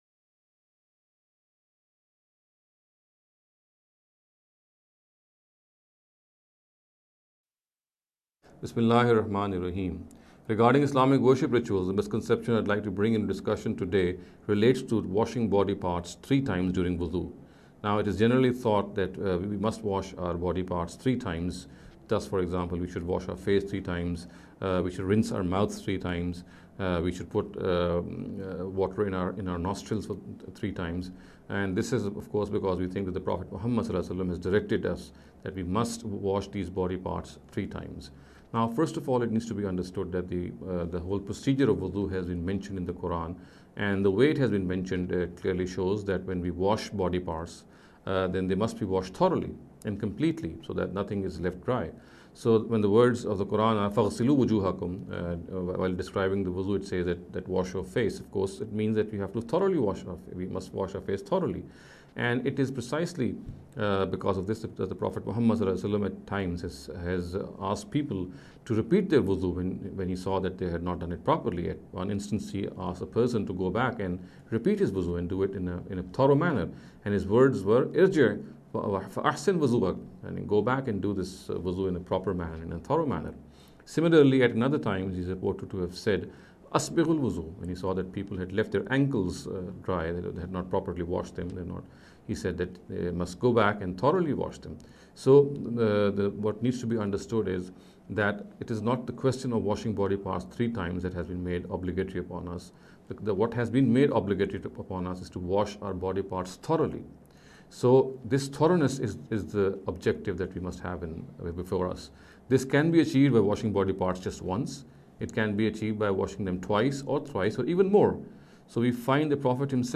This lecture series will deal with some misconception regarding Islamic worship rituals.